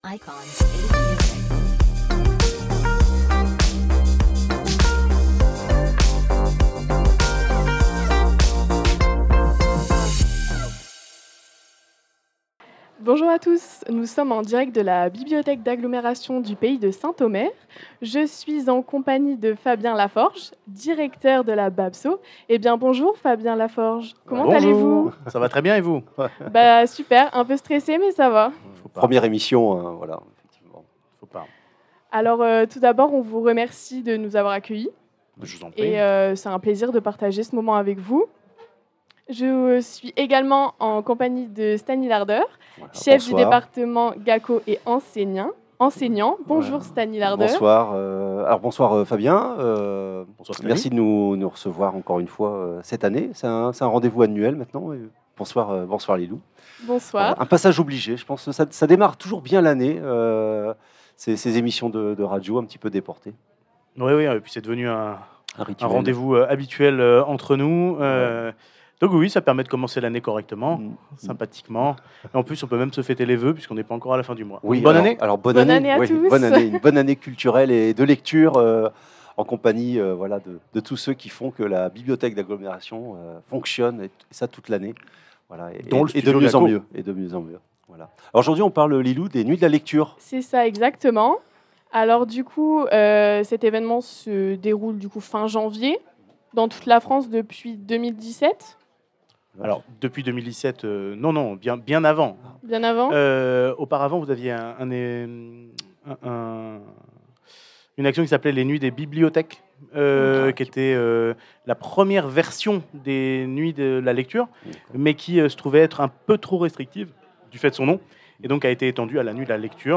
interview directeur T